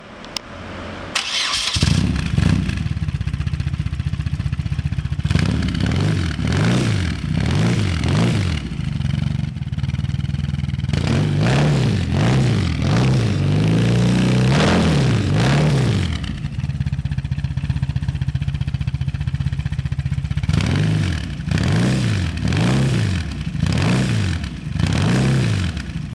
HATTECH Cannonball rustfrit stål lyddæmper sæt 40mm egnet til BMW R100RT R100RS R100S med 40 mm manifolddiameter, kort og kompakt, imponerende lyd.
Cannonball Sound.mp3